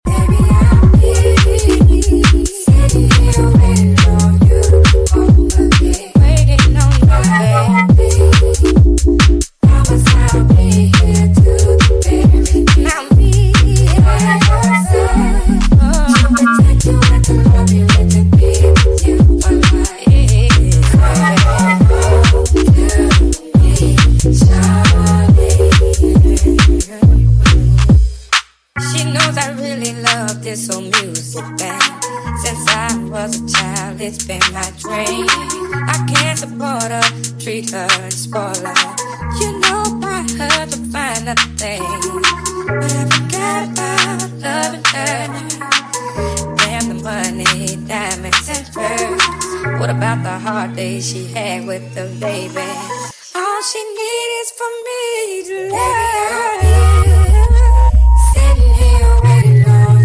1.2 Tsi intake sounds👀| DM sound effects free download